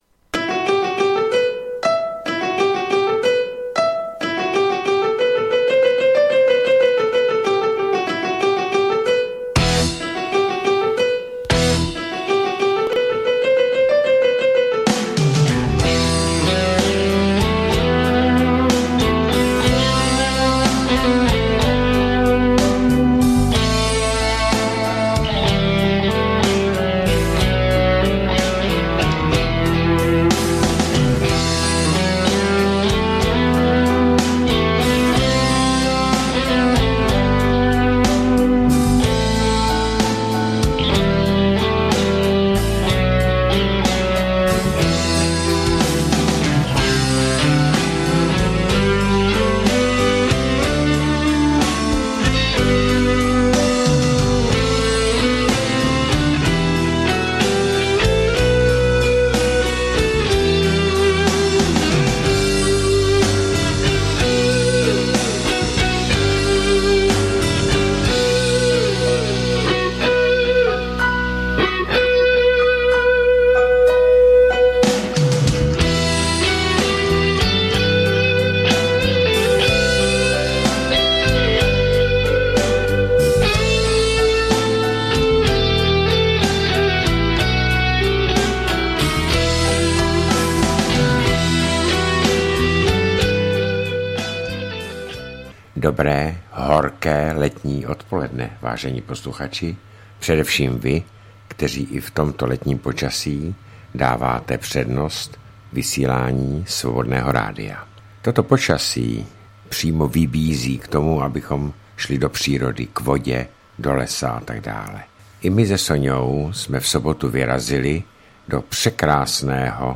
2017-05-29 - Studio Beta - Nad dopisy posluchačů s vašimi telefonáty.